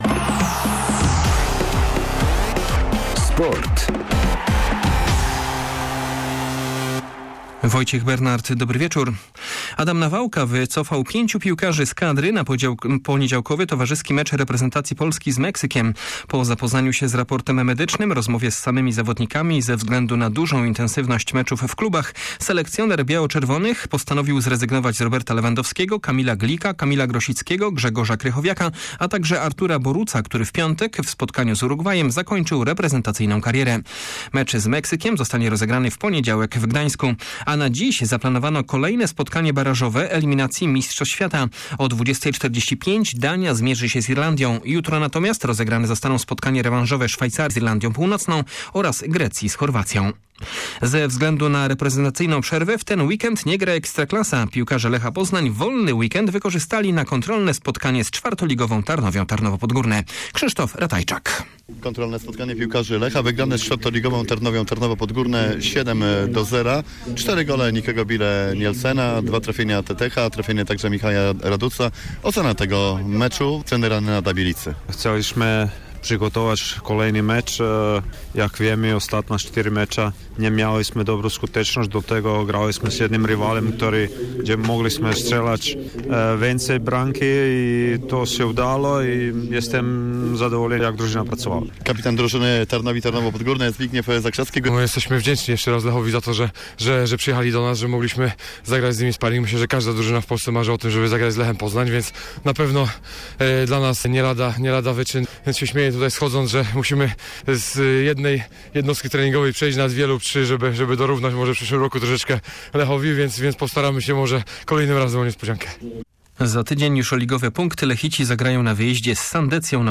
11.11 serwis sportowy godz. 19:05